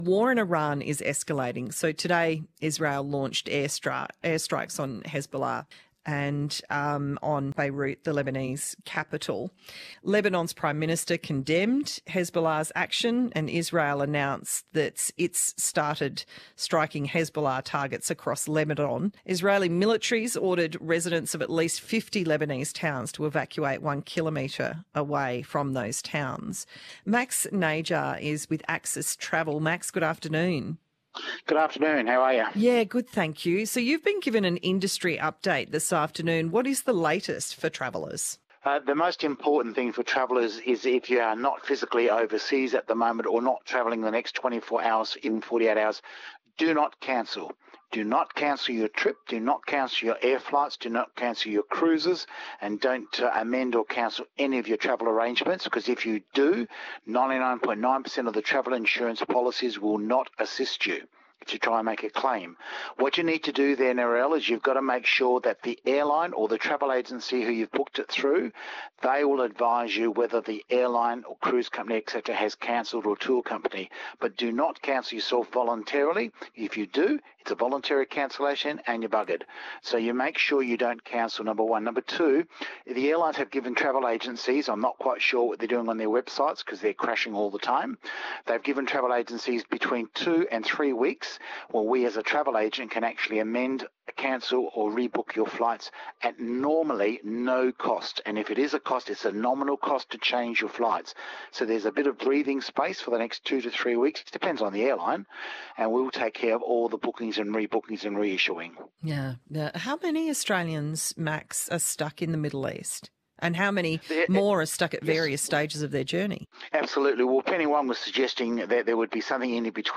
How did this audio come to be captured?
RADIO GRAB ABC